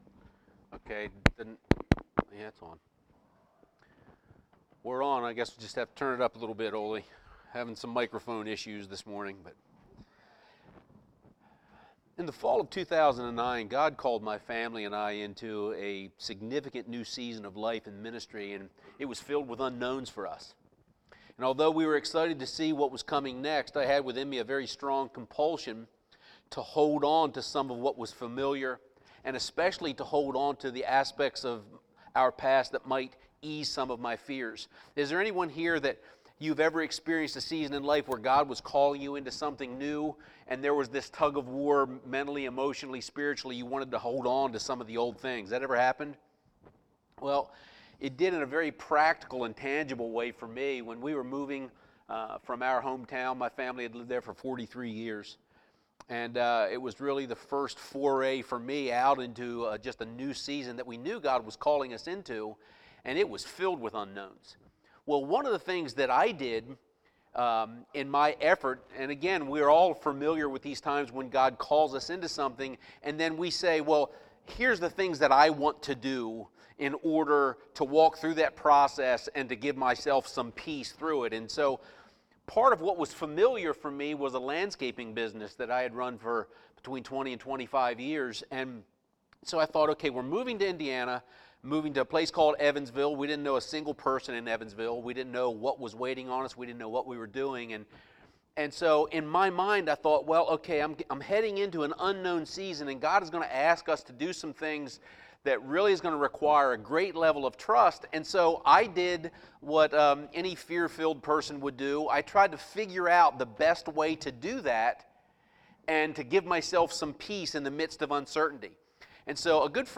5-6-18-sermon.mp3